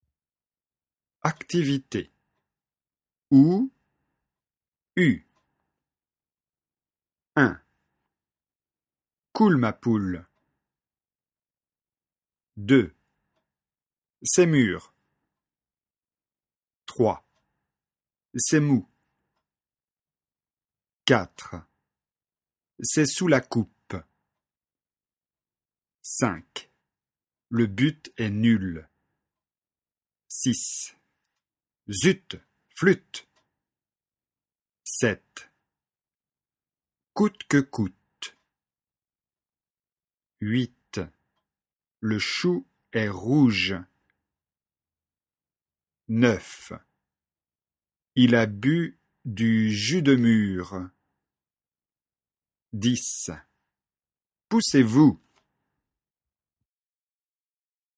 Leçon de phonétique, niveau débutant (A1).